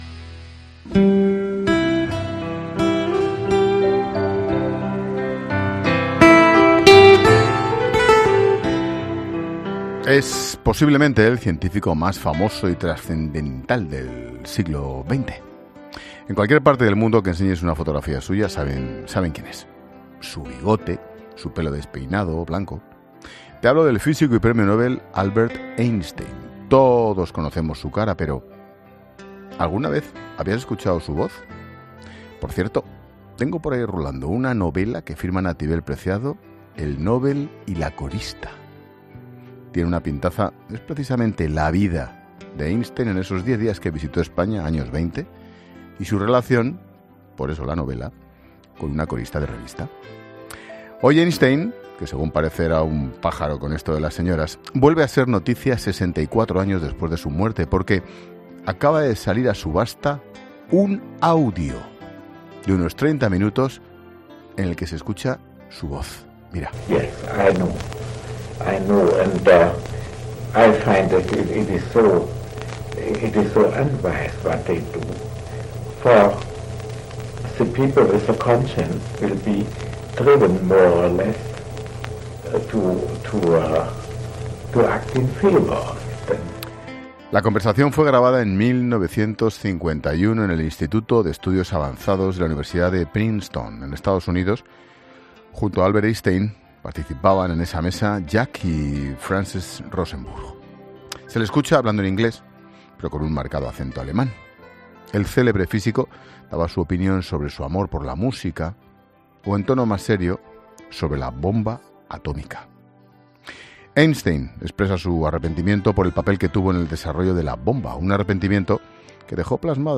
La conversación fue grabada en 1951 en el Instituto de Estudios Avanzados de la universidad de Princeton